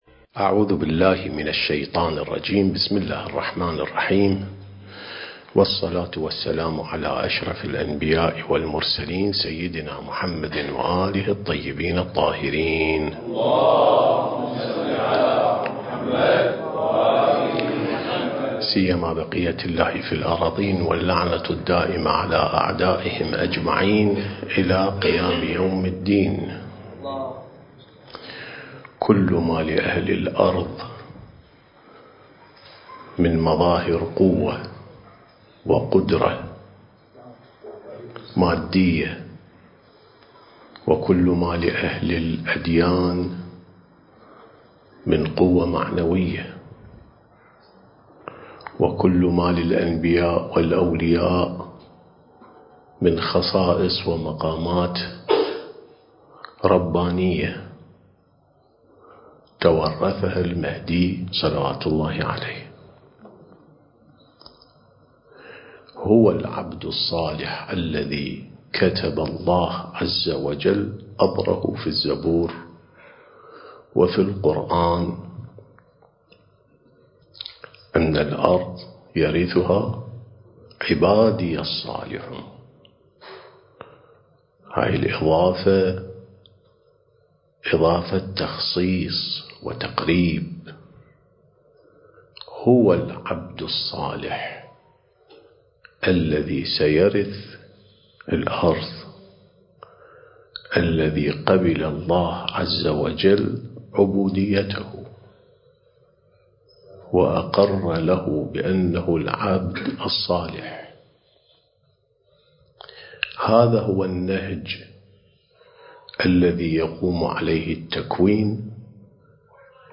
سلسلة محاضرات: الإعداد الربّاني للغيبة والظهور (3)